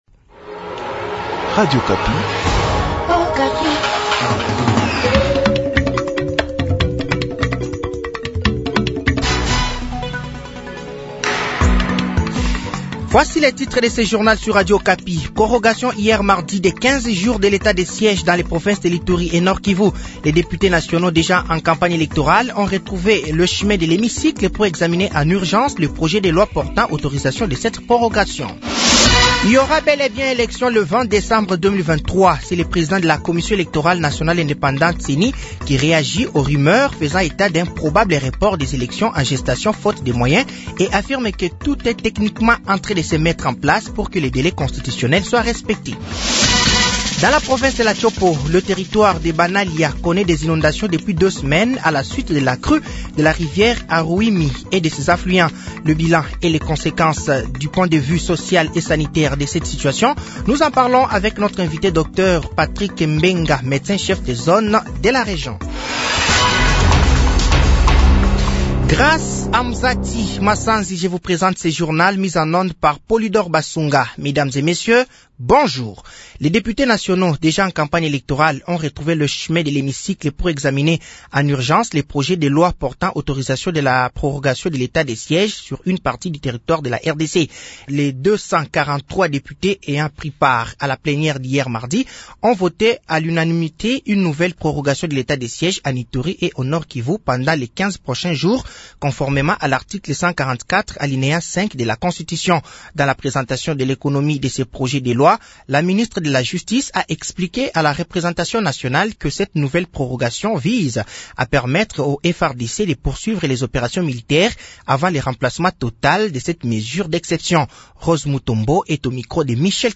Journal midi
Journal français de 12h de ce mercredi 29 novembre 2023